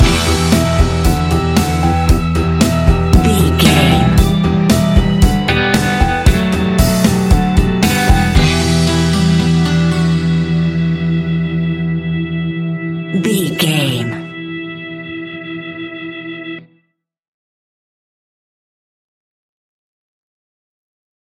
Aeolian/Minor
pop rock
indie pop
energetic
uplifting
motivational
drums
bass guitar
piano
electric guitar